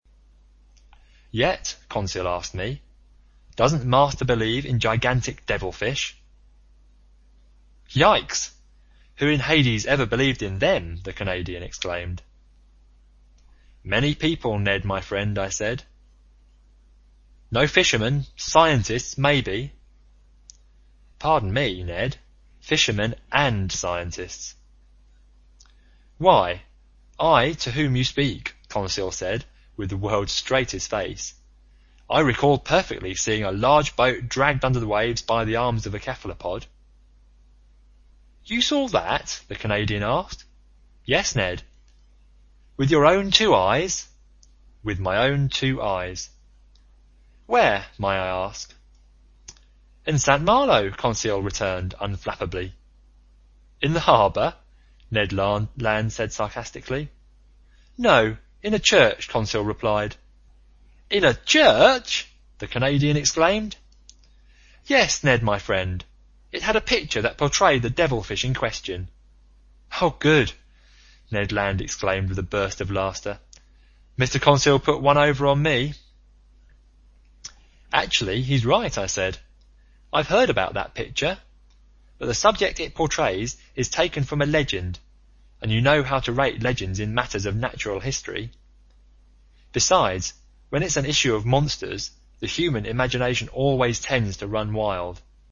在线英语听力室英语听书《海底两万里》第499期 第31章 章鱼(7)的听力文件下载,《海底两万里》中英双语有声读物附MP3下载